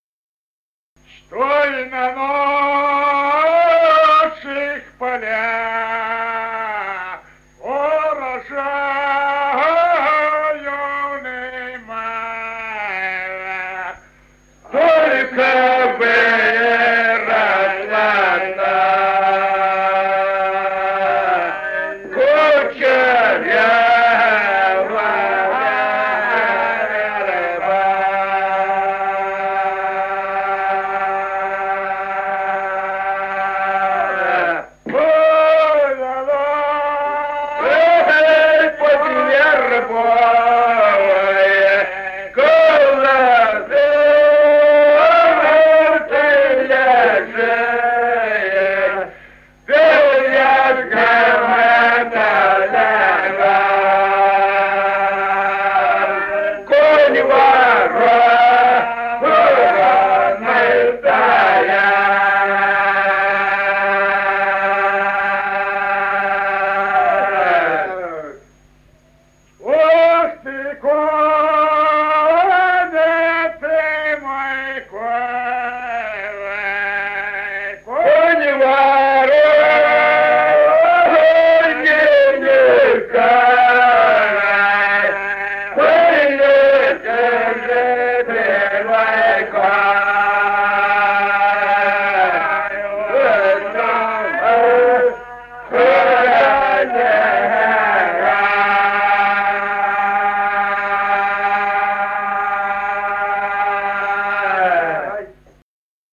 Песни села Остроглядово в записях 1950-х годов